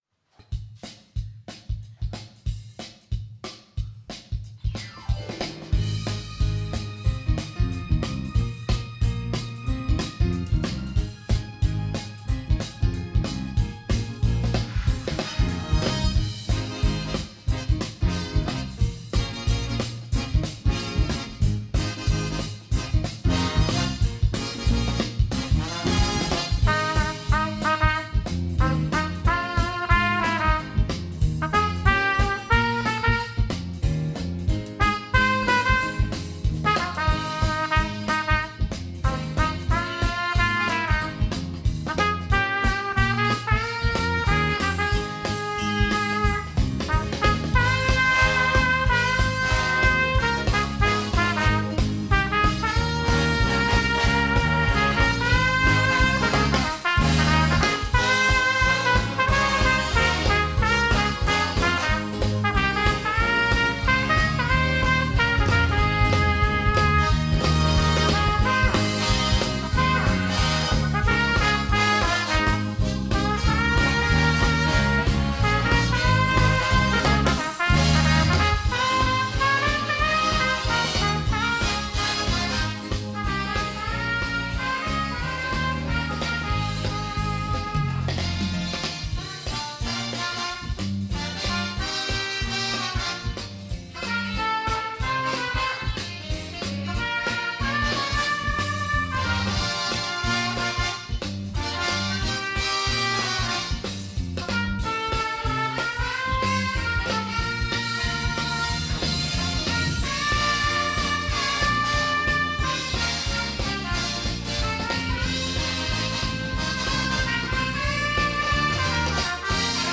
There is power in the blood - jazz orchestra version
Instrumental
Live recording